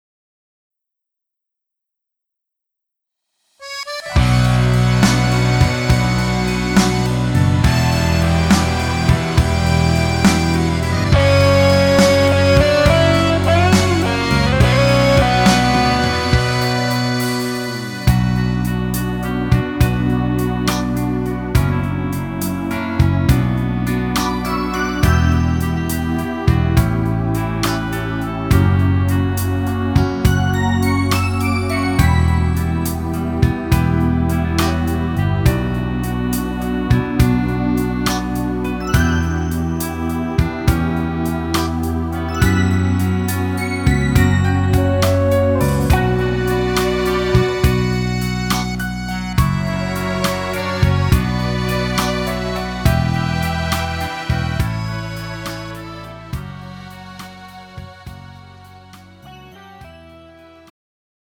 음정 -1키 3:57
장르 가요 구분 Pro MR
Pro MR은 공연, 축가, 전문 커버 등에 적합한 고음질 반주입니다.